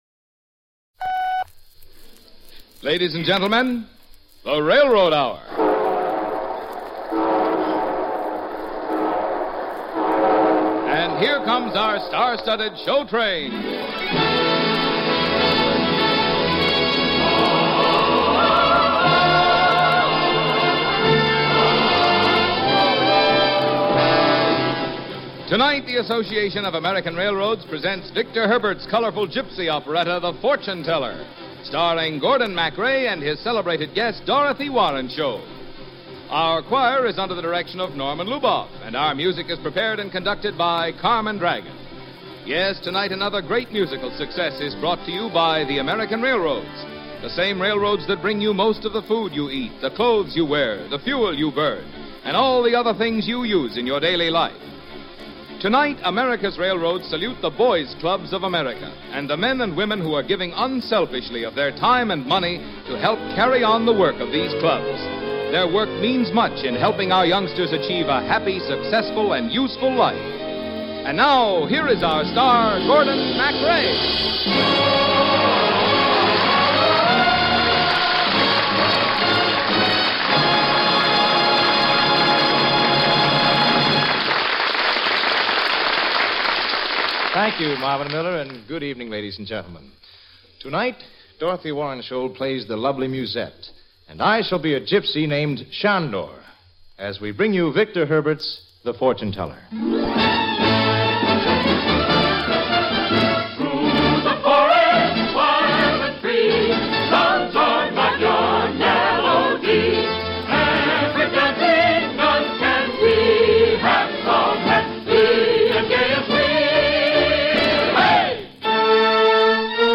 hosted each episode and played the leading male roles